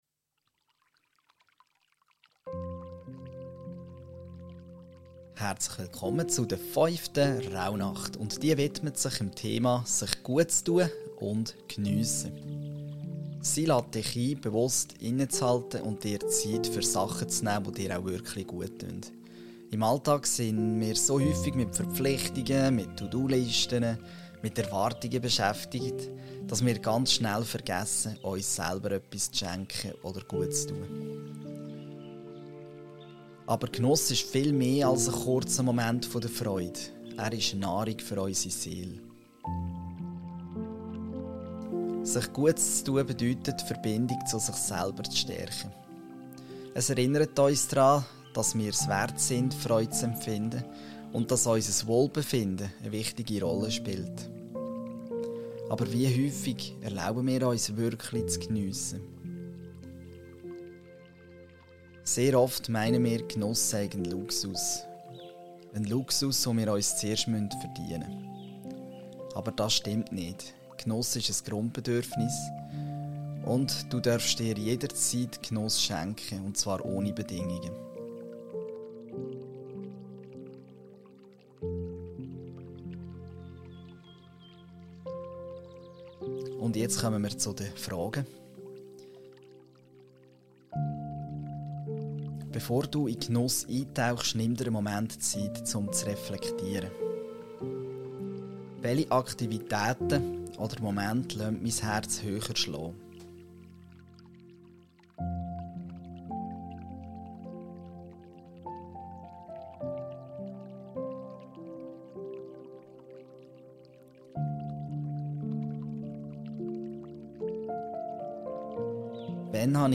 Der Seelefrede Hypnose Podcast für hochsensible Menschen
Die im Podcast verwendete Musik stammt von